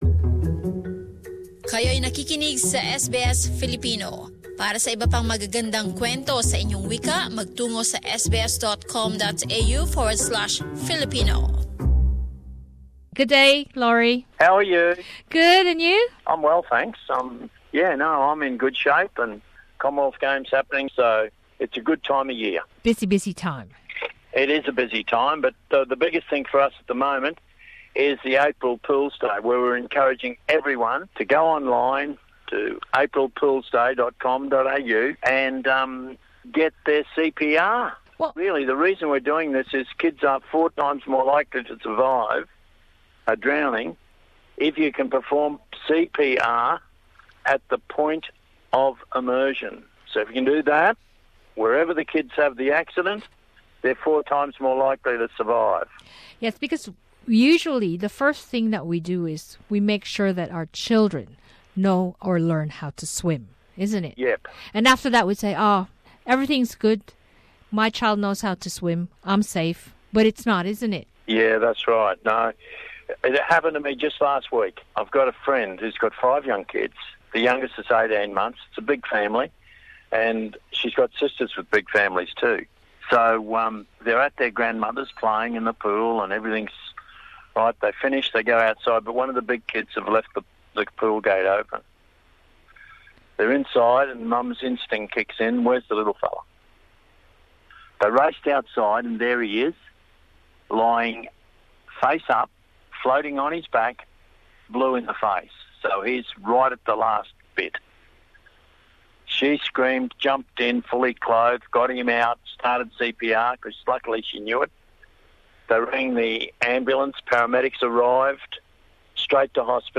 CPR might just save the life of your child. Australian swimming coach Laurie Lawrence tells us why we must take a few minutes to learn CPR.